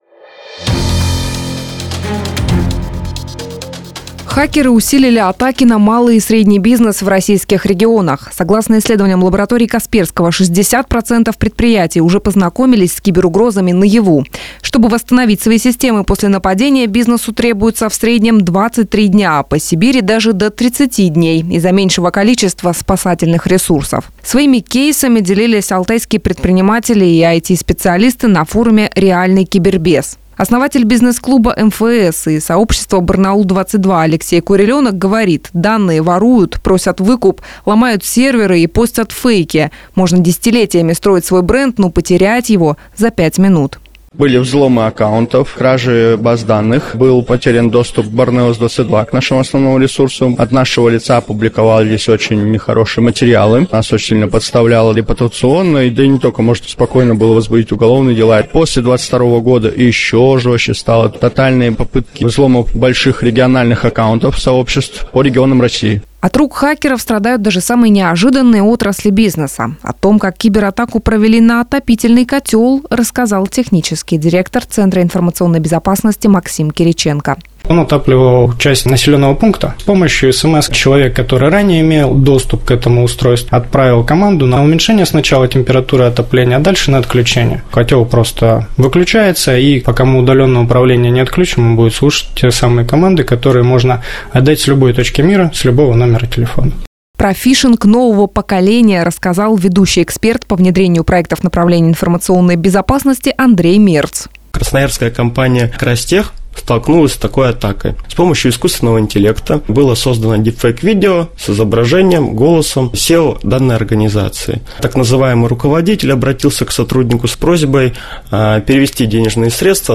Своими кейсами делились алтайские предприниматели и ИТ-специалисты на форуме "Реальный кибербез", отмечает радиостанция Business FM (Бизнес ФМ) Барнаул.
Сюжет на Business FM (Бизнес ФМ) Барнаул